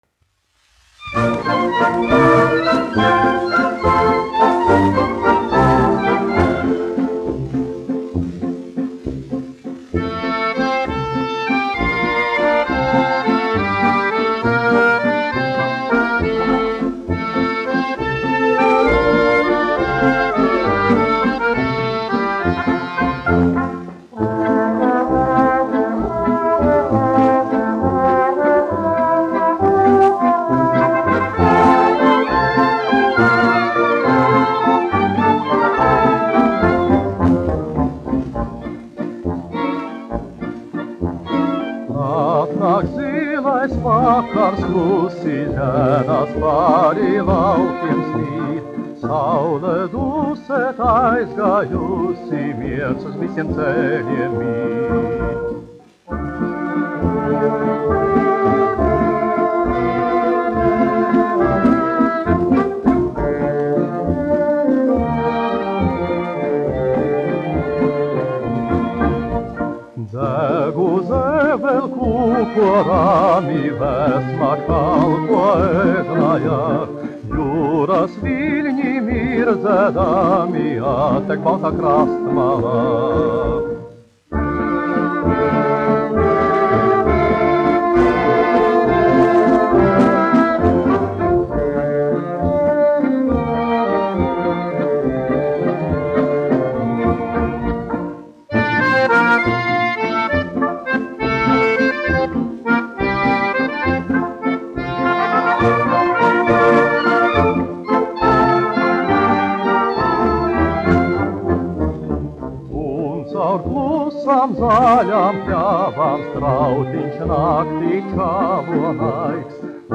dziedātājs
1 skpl. : analogs, 78 apgr/min, mono ; 25 cm
Populārā mūzika
Latvijas vēsturiskie šellaka skaņuplašu ieraksti (Kolekcija)